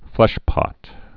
(flĕshpŏt)